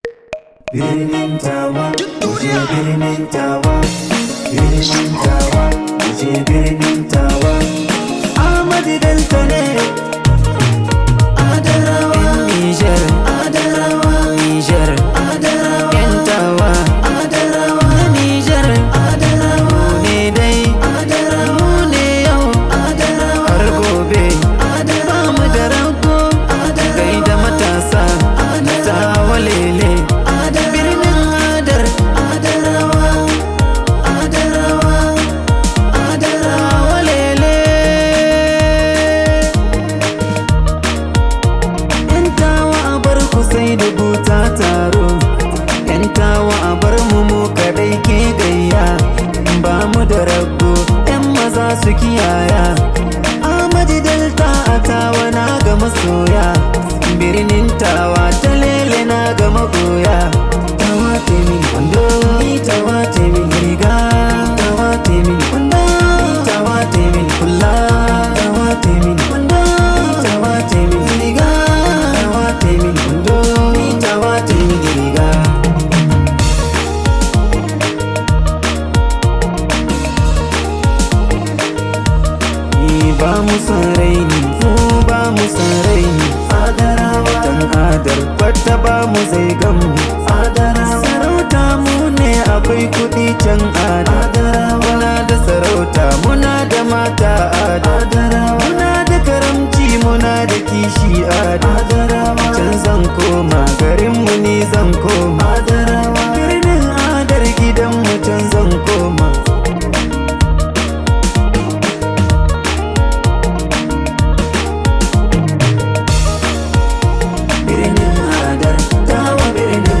top rated Nigerian Hausa Music artist
high vibe hausa song